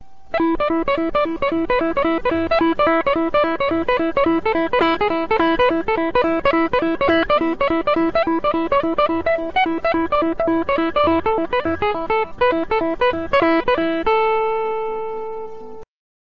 Picking
Clean
PICKING.mp3